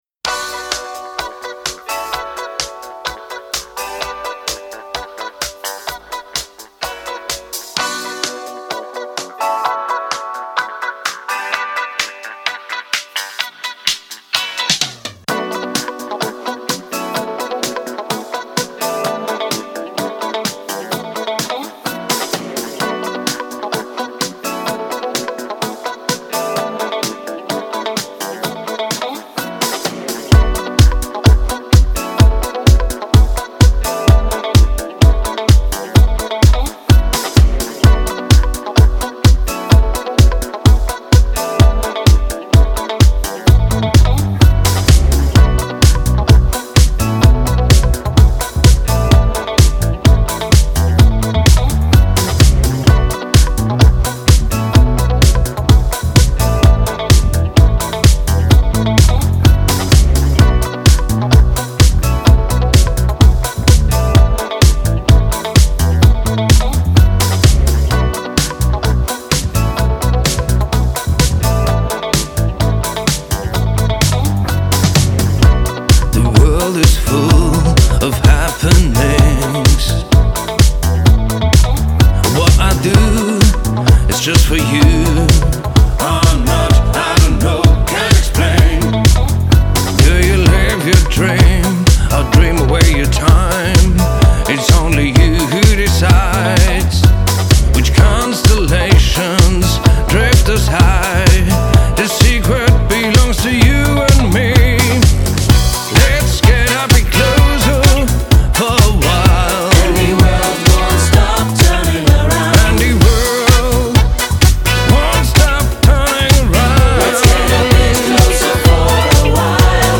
Backing Vocals
Bass